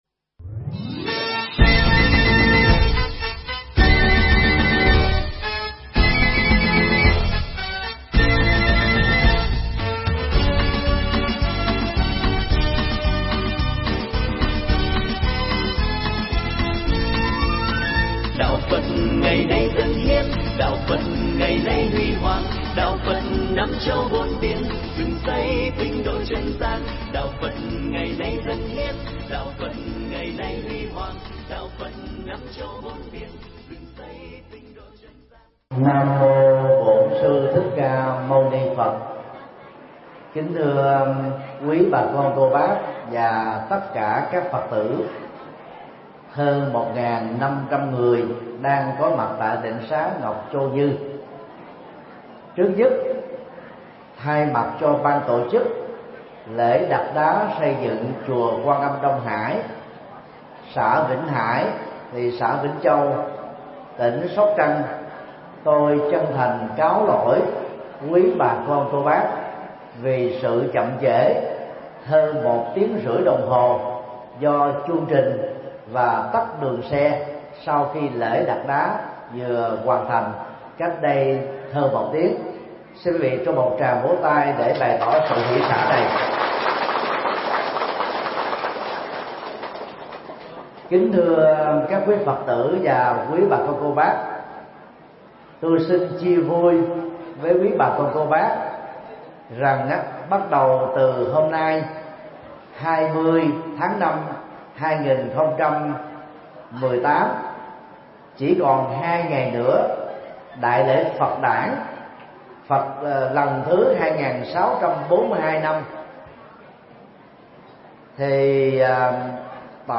Mp3 Pháp Thoại Vượt Qua Mặc Cảm Về Thân Phận – Thượng Tọa Thích Nhật Từ giảng cho 1500 người khiếm thị tại Tịnh xá Ngọc Châu Như, Sóc Trăng